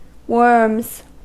Ääntäminen
Ääntäminen US Tuntematon aksentti: IPA : /wɜːms/ IPA : /wɝms/ Haettu sana löytyi näillä lähdekielillä: englanti Käännöksiä ei löytynyt valitulle kohdekielelle.